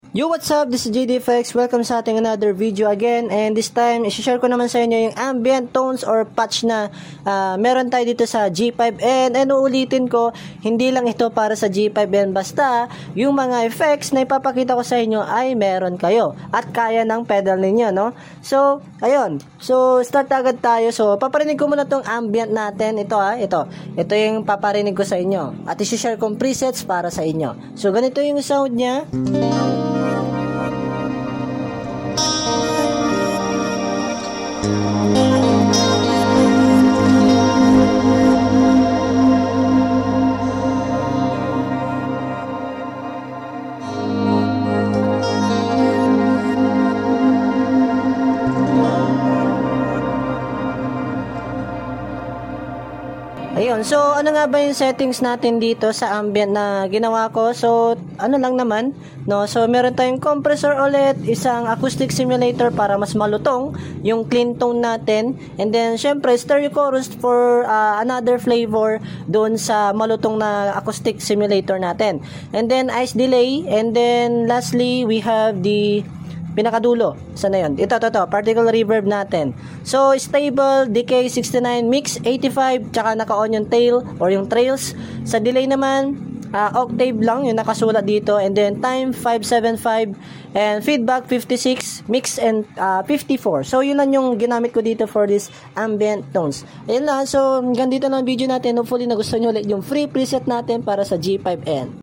ZOOM G5n - Ambient/Shimmer FREE